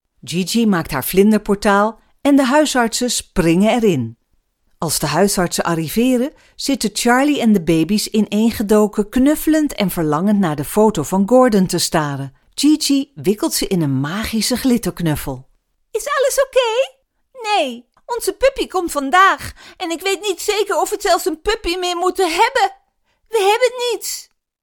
Native speakers